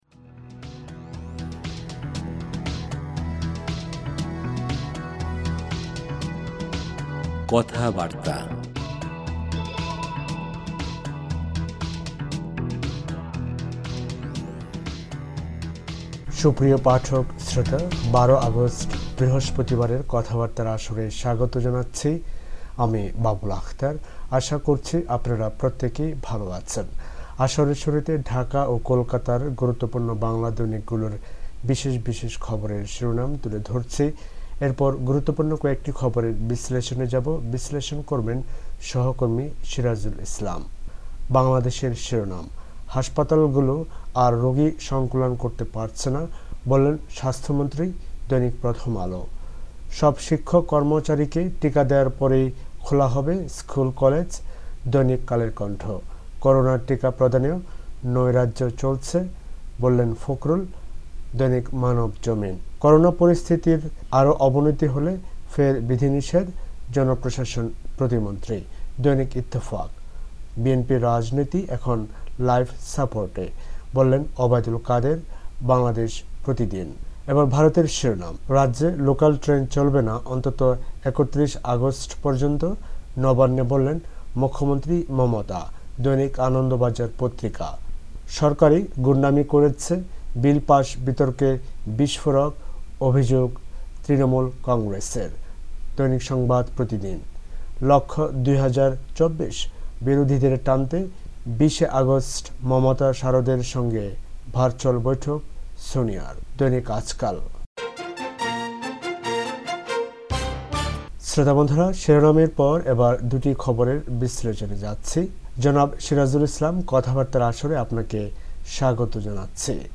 আসরের শুরুতে ঢাকা ও কোলকাতার গুরুত্বপূর্ণ বাংলা দৈনিকগুলোর বিশেষ বিশেষ খবরের শিরোনাম তুলে ধরছি। এরপর গুরুত্বপূর্ণ কয়েকটি খবরের বিশ্লেষণে যাবো।